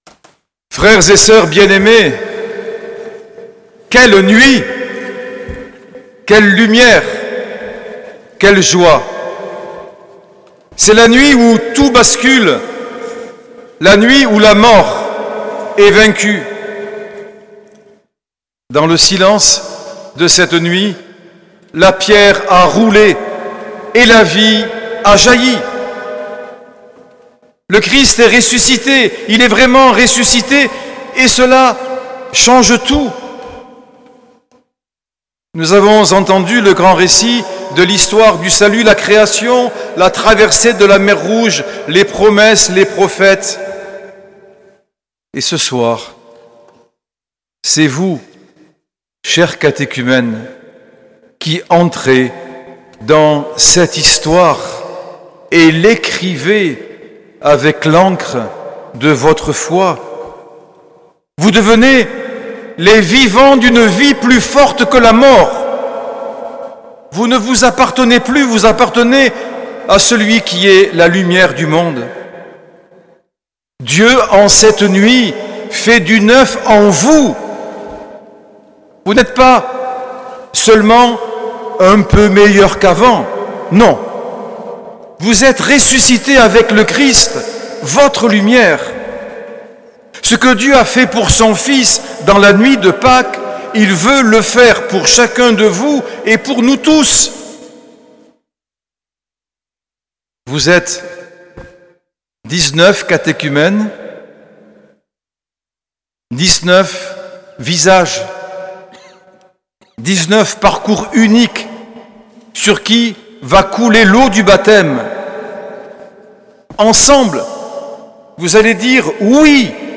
Homélie de Monseigneur Norbert TURINI, nuit du 19 au 20 avril 2025, veillée pascale
L’enregistrement retransmet l’homélie de Monseigneur Norbert TURINI.